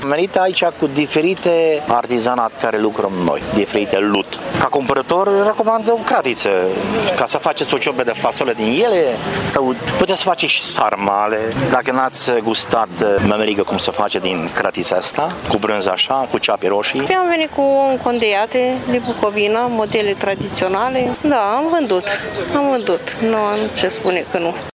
Au venit meșteșugari din toată țara, cu cele mai diverse produse, de la obiecte de decor, la costume populare, oale, fluiere și flori: